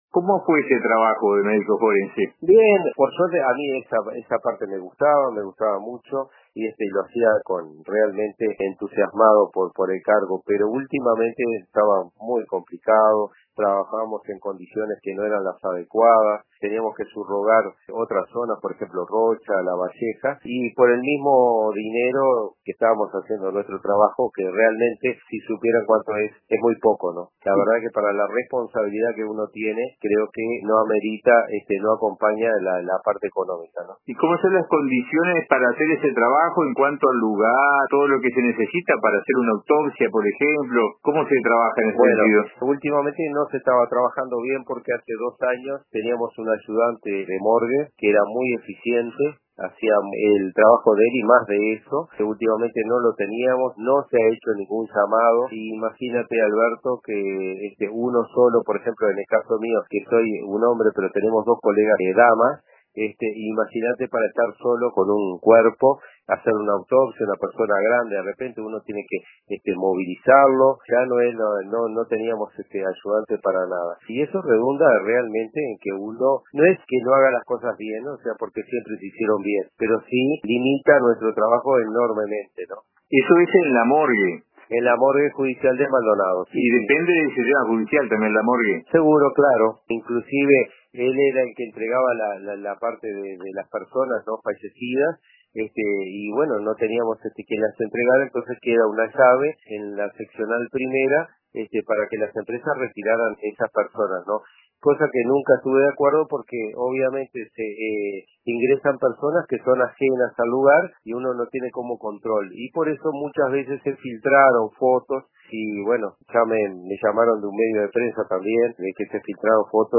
En entrevista con Informativo de RADIO RBC